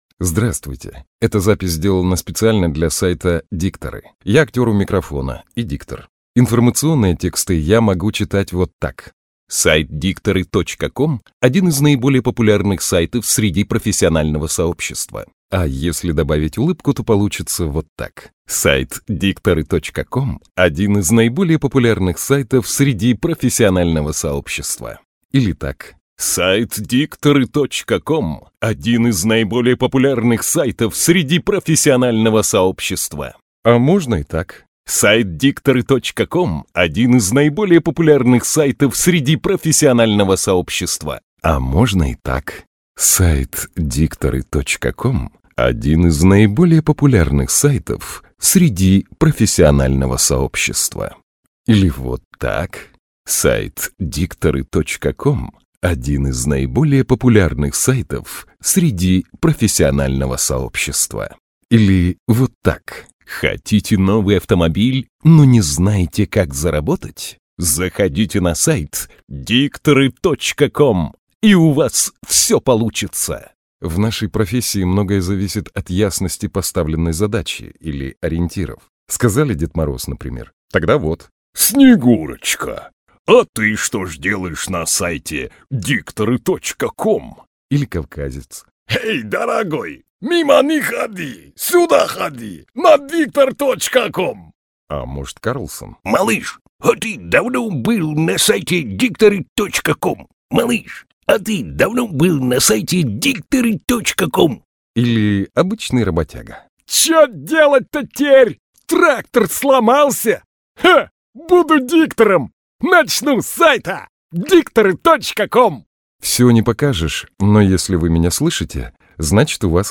озвучка игровых, рекламных, информационных текстов
Микрофон: RODE NTK